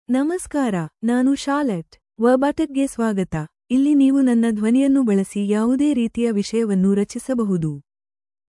FemaleKannada (India)
Charlotte is a female AI voice for Kannada (India).
Voice sample
Female
Charlotte delivers clear pronunciation with authentic India Kannada intonation, making your content sound professionally produced.